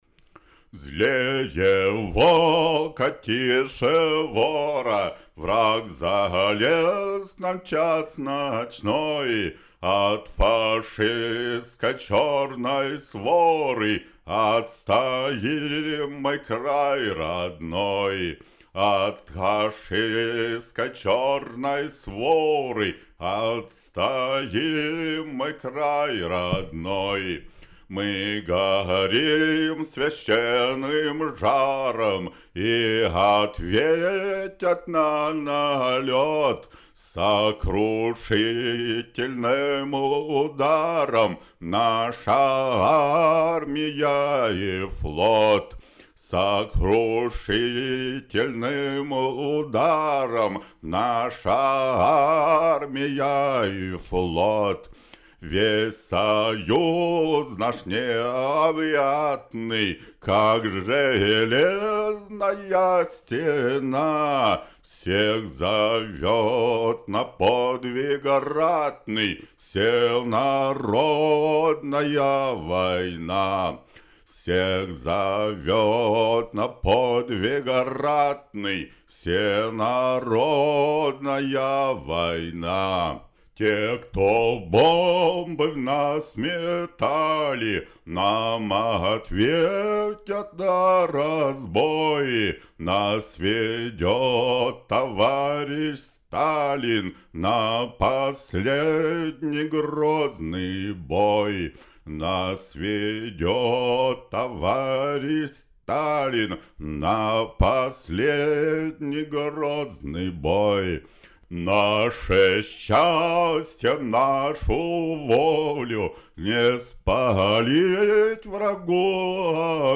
Песня в моем исполнении на суд форумчан